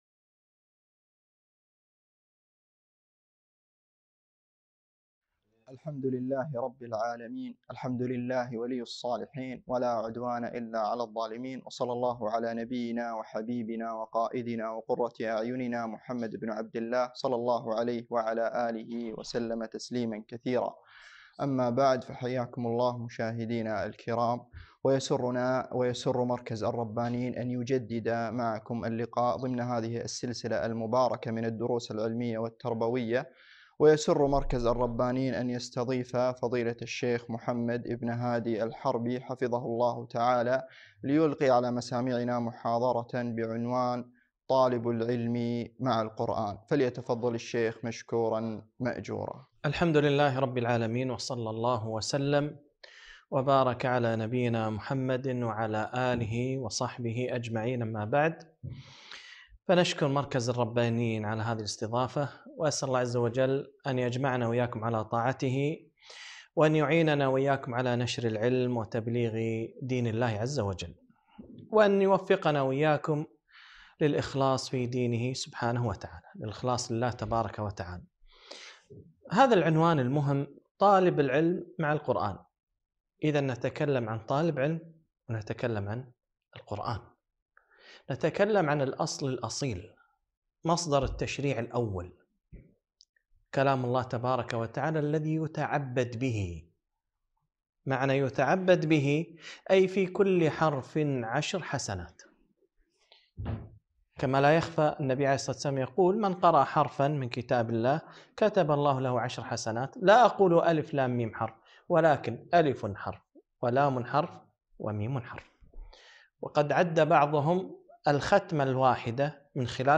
محاضرة - طالب العلم مع القرآن